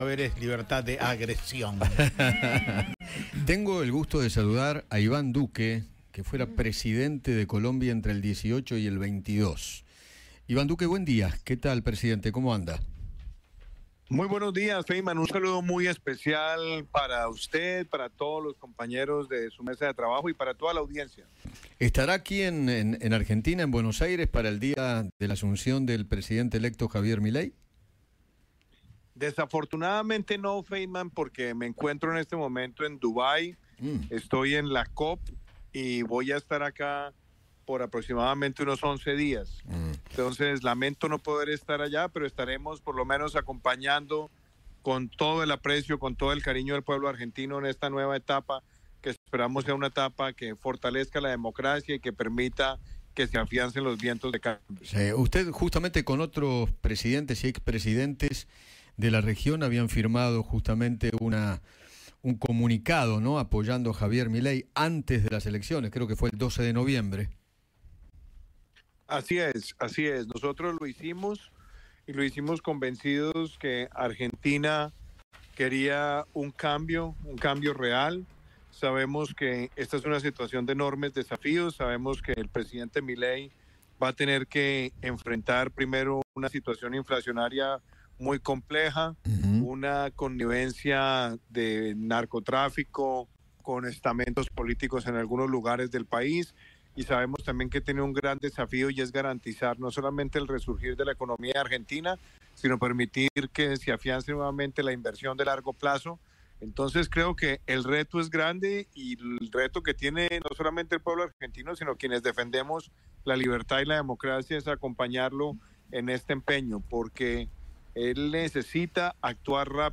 Iván Duque, ex presidente de Colombia, conversó con Eduardo Feinmann sobre la situación que deberá enfrentar Javier Milei una vez que asuma la presidencia y advirtió al libertario sobre el grupo de Puebla.